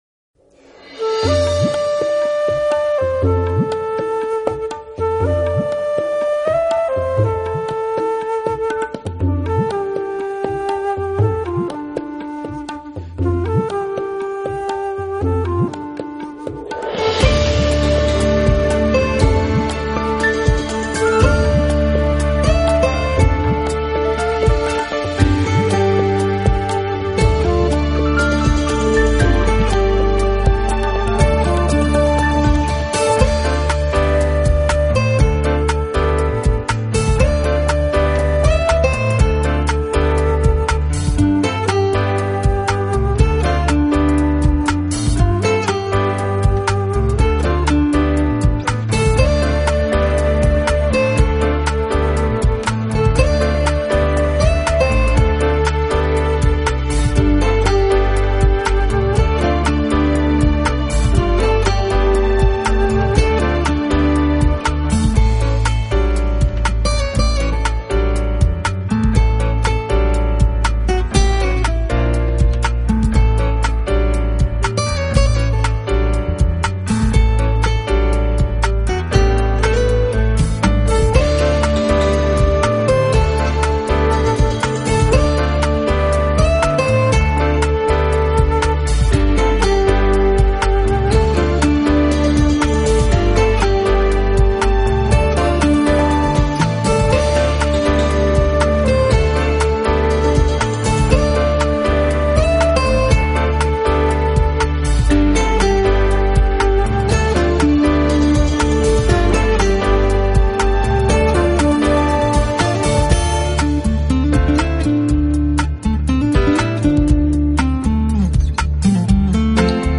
音乐类型: smooth jazz
演奏技巧结合Hip Hop节拍，打造极具现代都会质感的个人音乐风格。